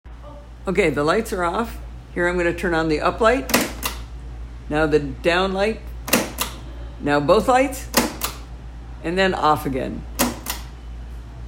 The ribbon is attached to a very strong mechanism that makes a super loud clunking sound each time you pull down to mechanically change the light setting.
Loud Hospital Lights